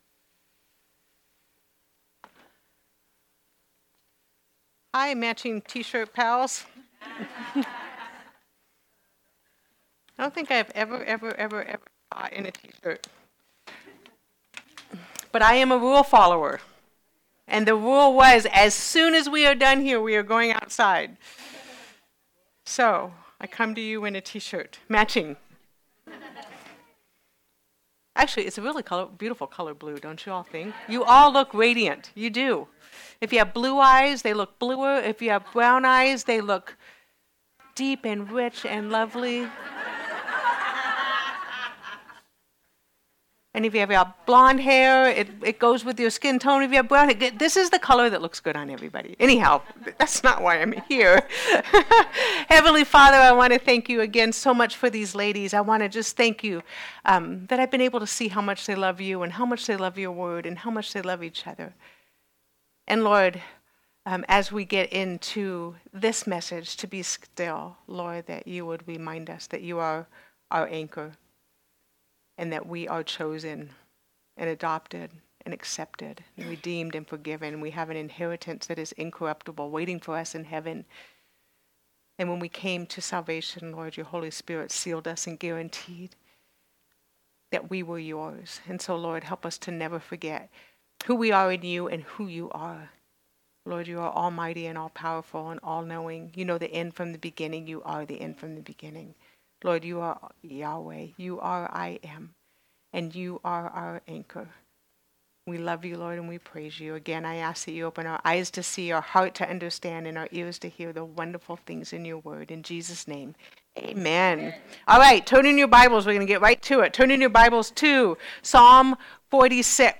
Sermons | Calvary Chapel Pahrump Valley